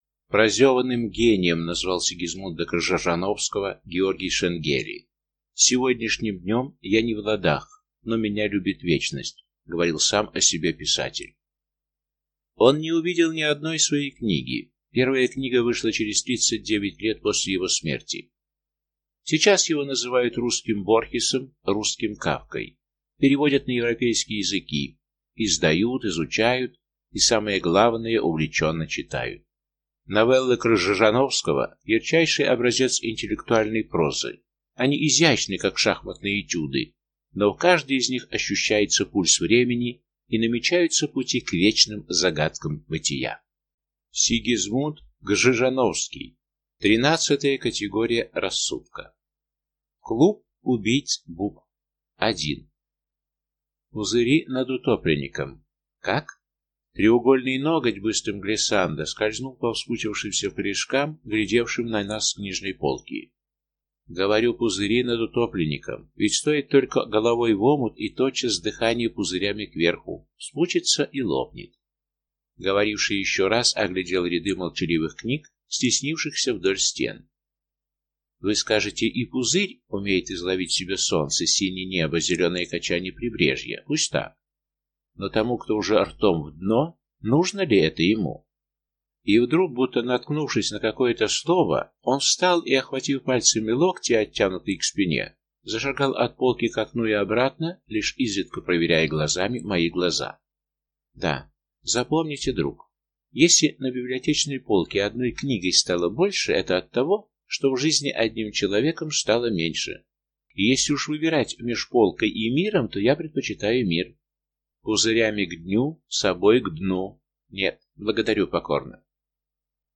Аудиокнига Тринадцатая категория рассудка | Библиотека аудиокниг